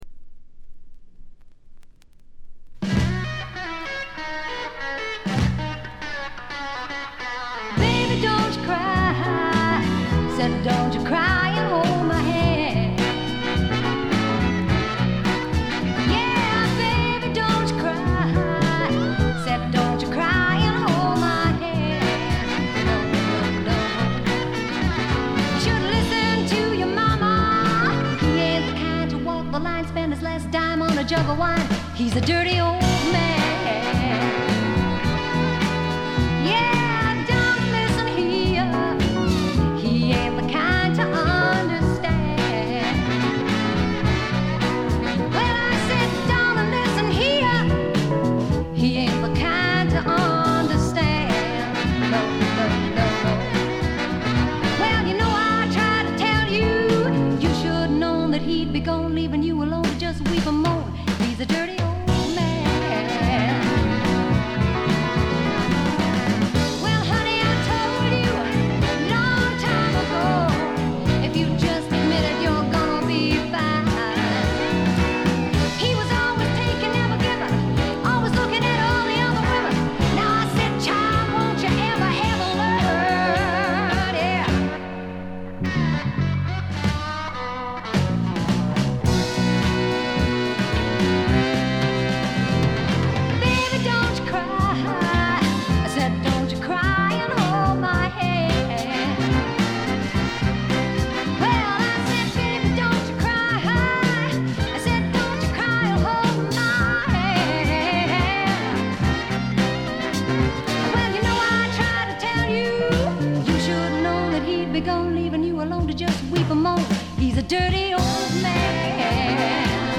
部分試聴ですが、ごくわずかなノイズ感のみ。
試聴曲は現品からの取り込み音源です。